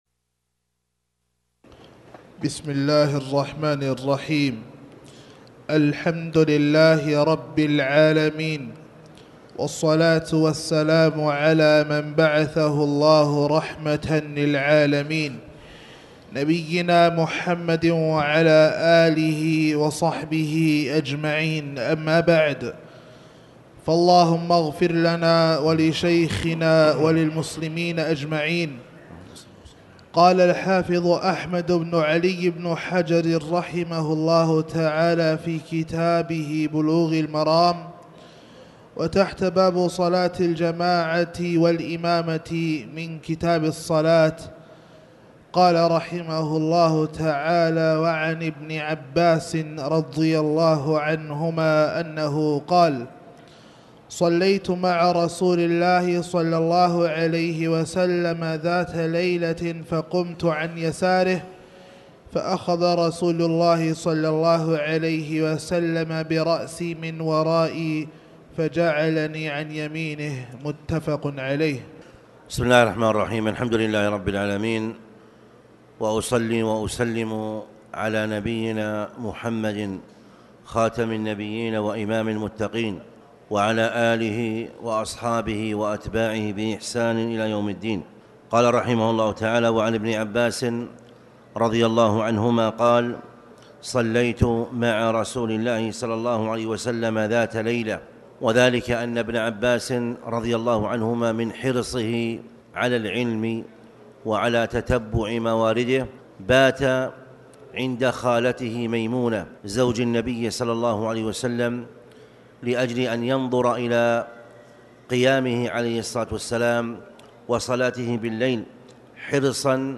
تاريخ النشر ٢٧ صفر ١٤٣٩ هـ المكان: المسجد الحرام الشيخ